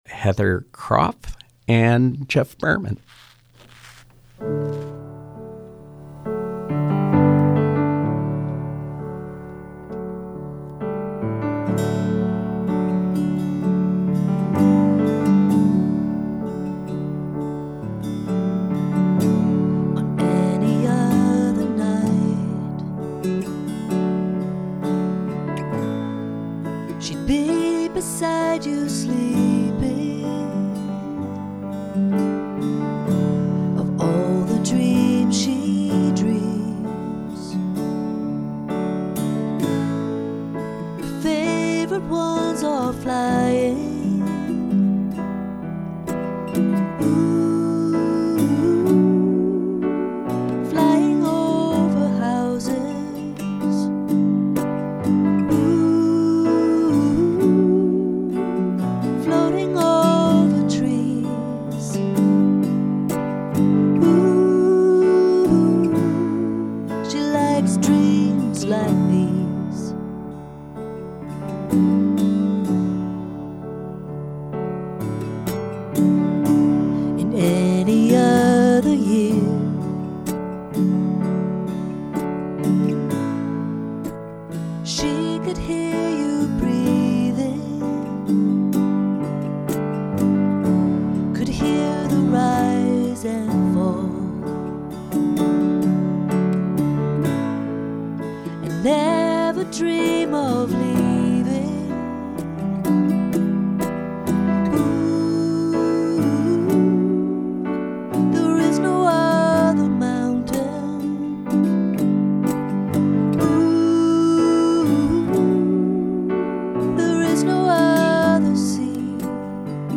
mountain dulcimer